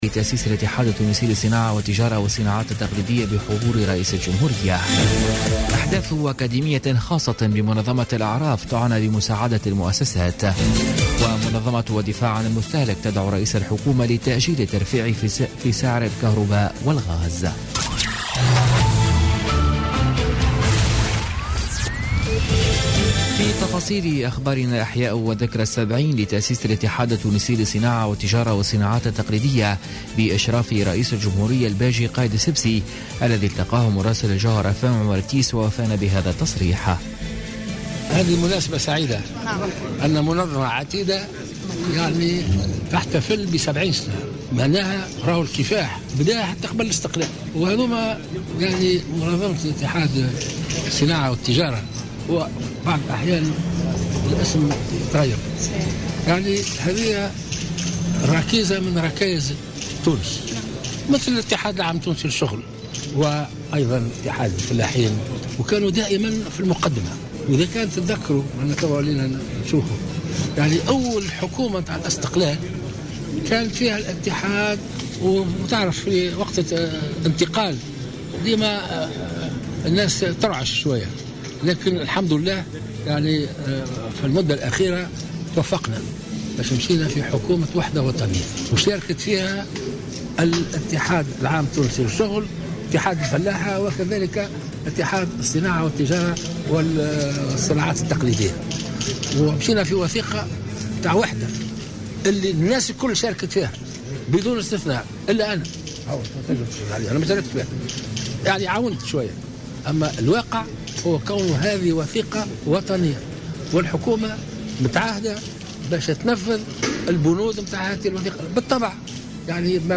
نشرة أخبار منتصف الليل ليوم الأربعاء 18 جانفي 2017